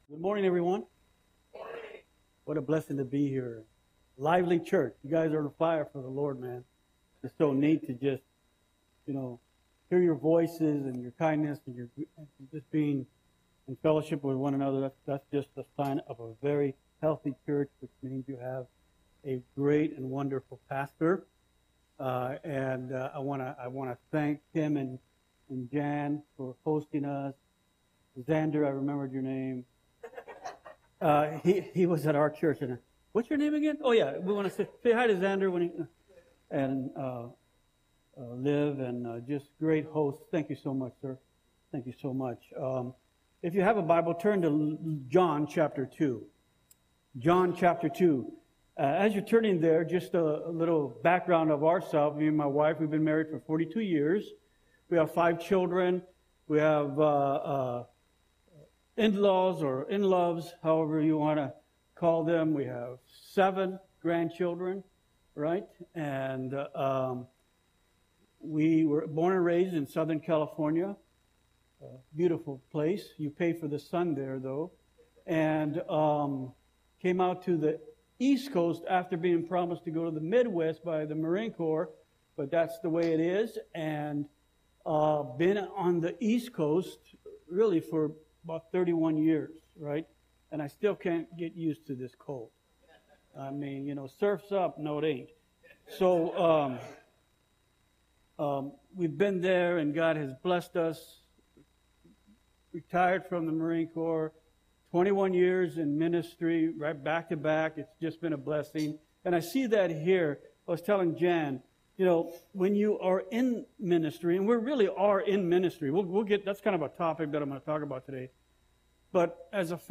Audio Sermon - February 23, 2025